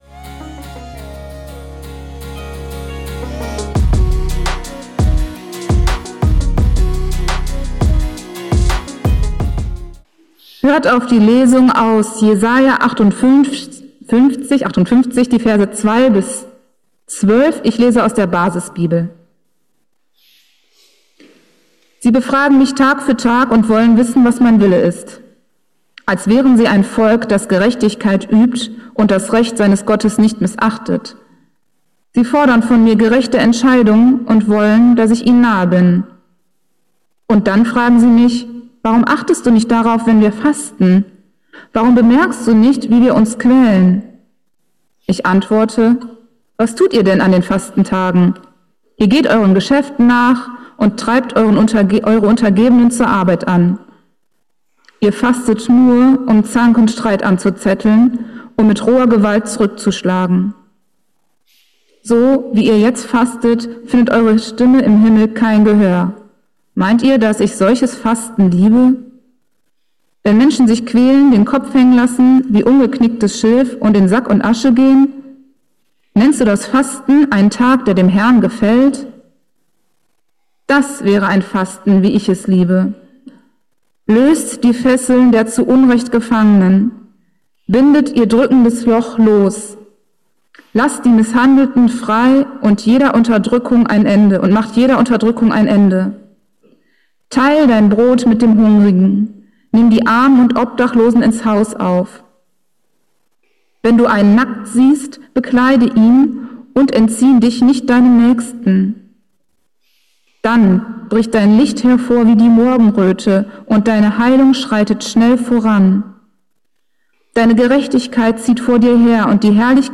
Fasten - um Armen zu helfen ~ Geistliche Inputs, Andachten, Predigten Podcast